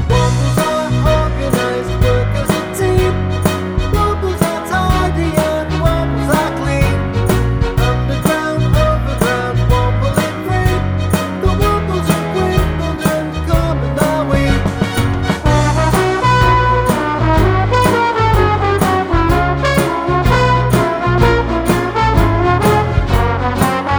no Backing Vocals